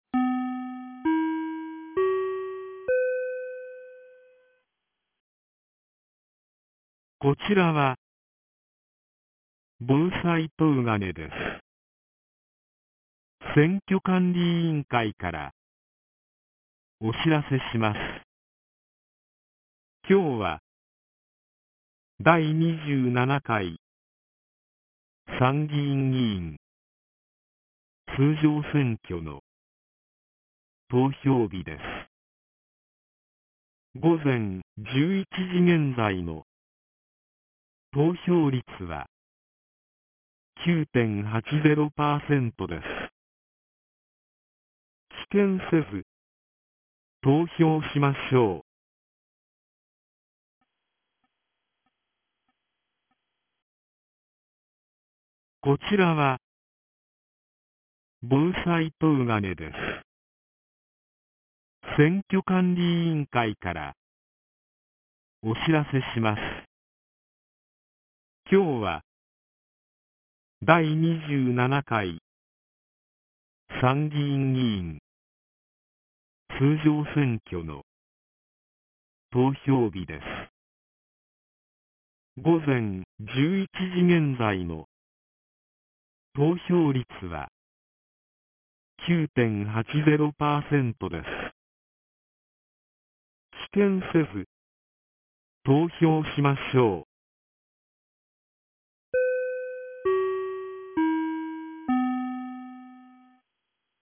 2025年07月20日 11時12分に、東金市より防災行政無線の放送を行いました。